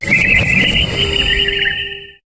Cri de Zéroïd dans Pokémon HOME.